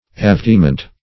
Avertiment \A*ver"ti*ment\, n.